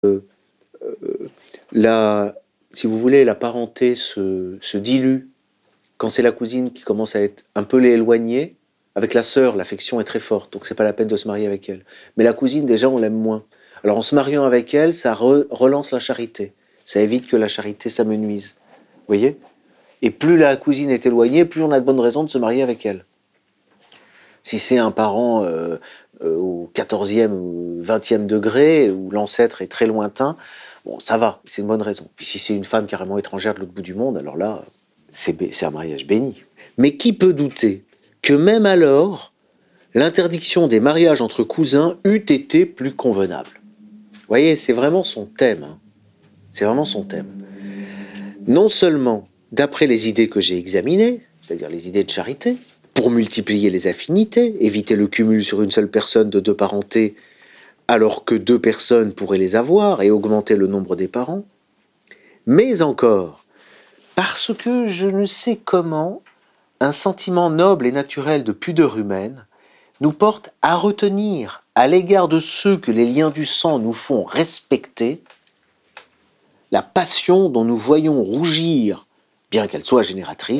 Le séminaire « l’inceste chez Saint Augustin » dure une heure, c’est le live d’un cours de droit que j’ai délivré dans le cadre des Formations d’Egalité et Réconciliation.